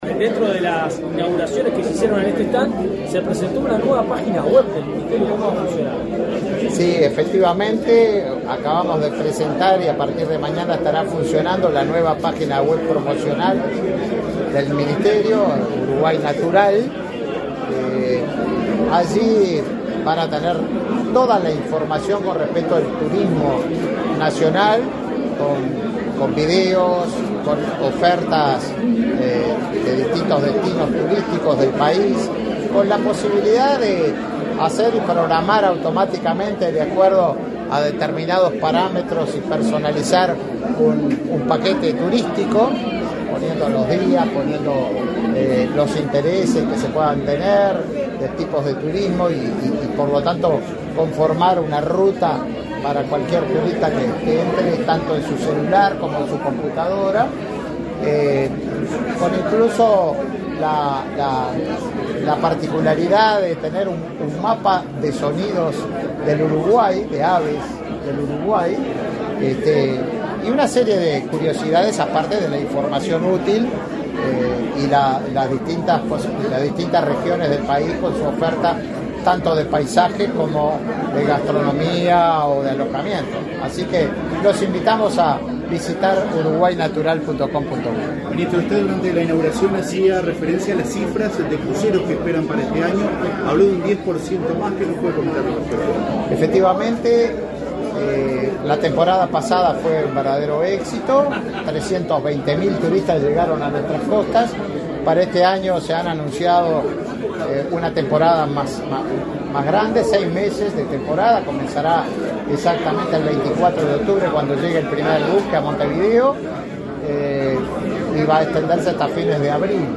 Declaraciones a la prensa del ministro de Turismo, Tabaré Viera
Declaraciones a la prensa del ministro de Turismo, Tabaré Viera 11/09/2023 Compartir Facebook X Copiar enlace WhatsApp LinkedIn Tras participar en la inauguración del stand del Ministerio de Turismo en la Expo Prado 2023, este 11 de setiembre, el ministro Tabaré Viera realizó declaraciones a la prensa.